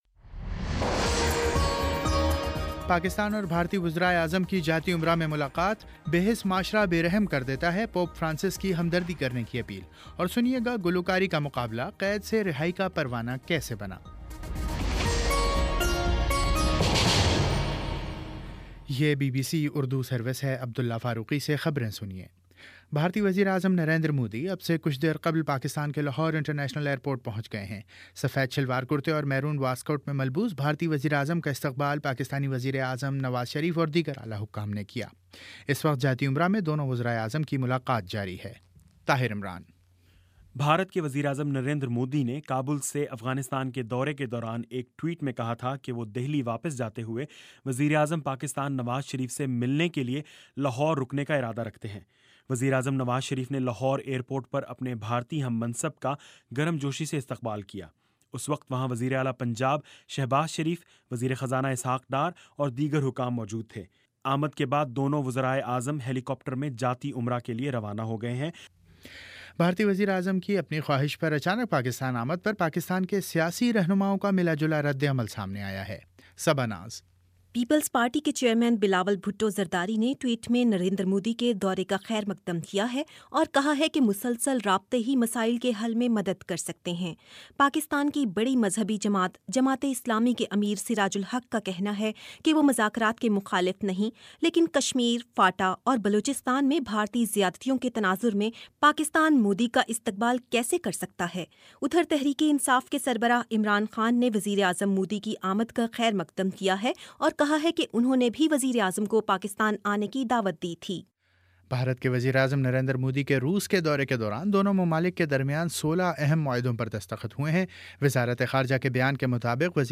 دسمبر 25 : شام چھ بجے کا نیوز بُلیٹن